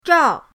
zhao4.mp3